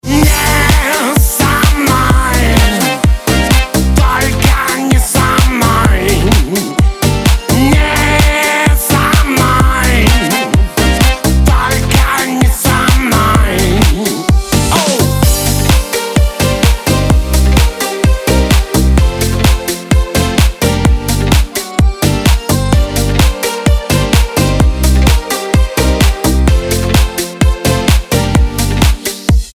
• Качество: 320, Stereo
мужской вокал
громкие
dance